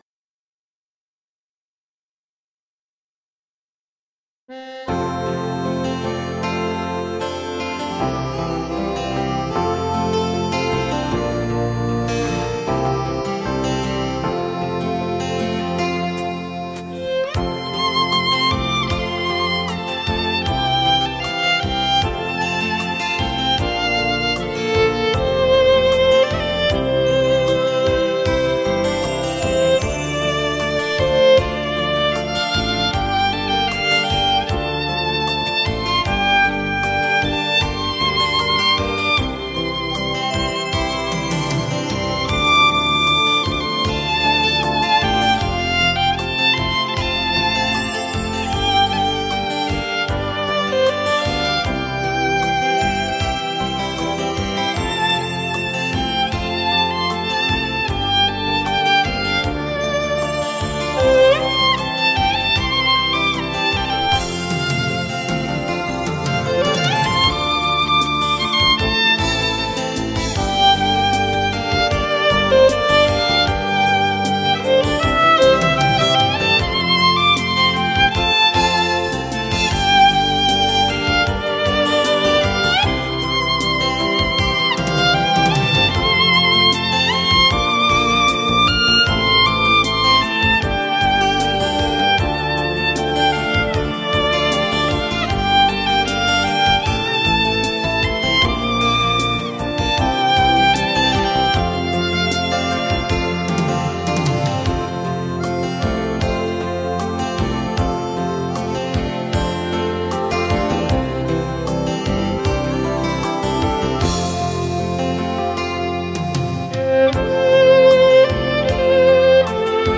小提琴独奏
浪漫金曲愈久弥笃，精湛技艺深情投入，旋律温婉悦耳圆润，琴韵悠扬耐人寻味。